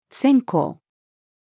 専攻-female.mp3